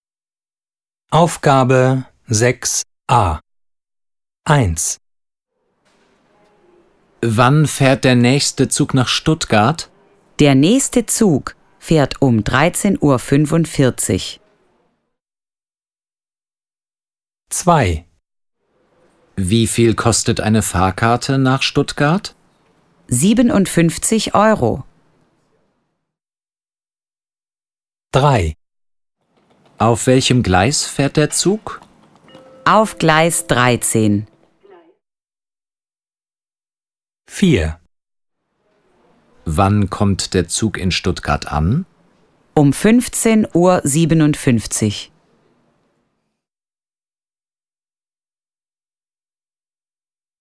Sie hören den Text zweimal.